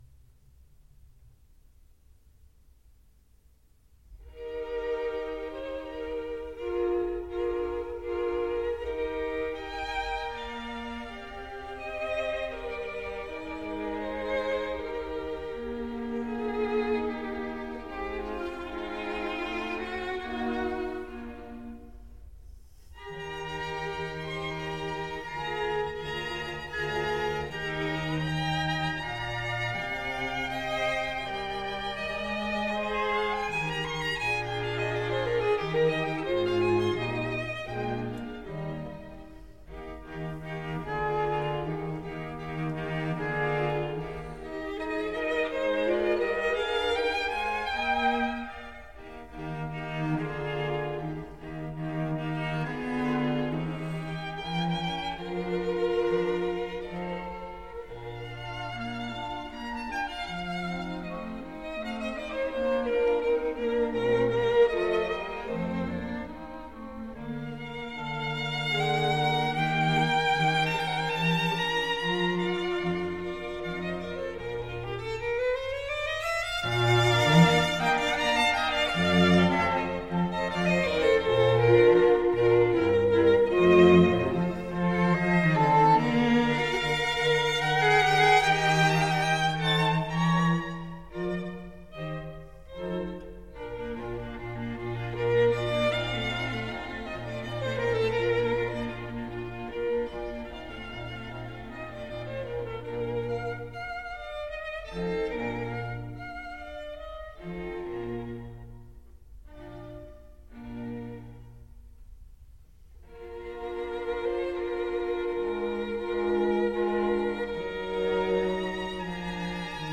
Quintet